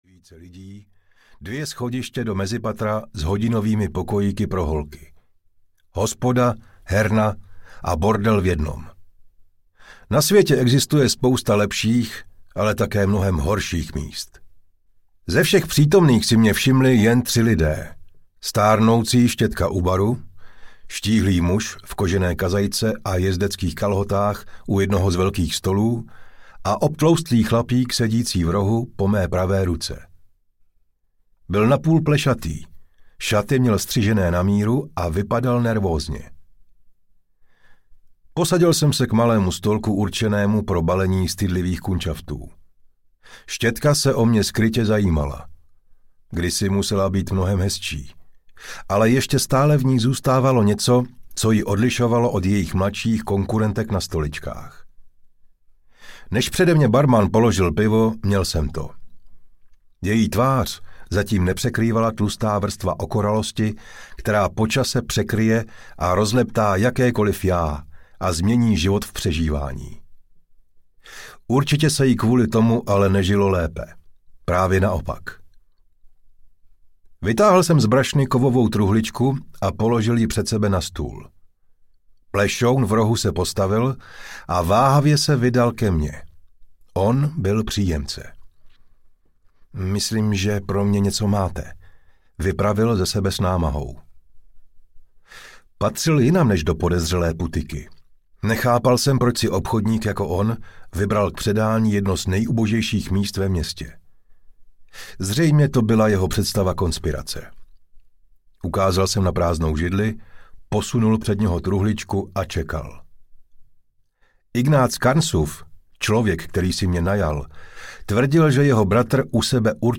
Bakly – Bez slitování audiokniha
Ukázka z knihy